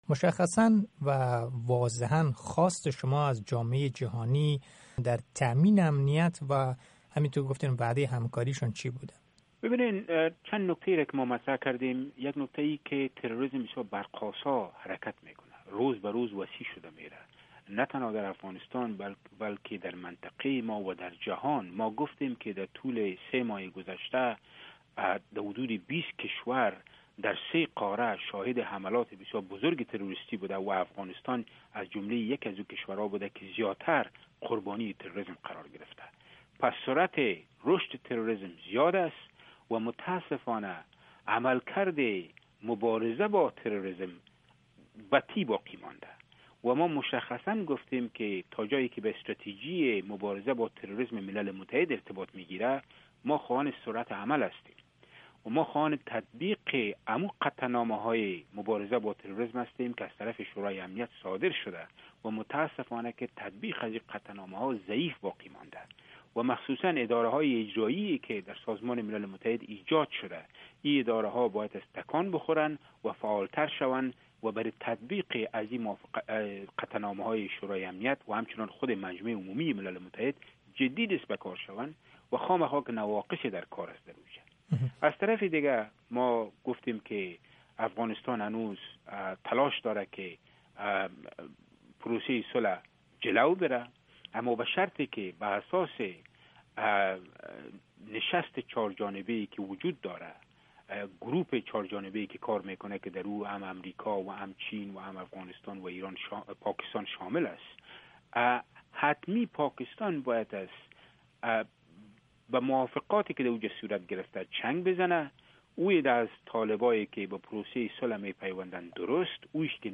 مصاحبه با محمود صیقل، سفیر و نماینده دائمی افغانستان در ملل متحد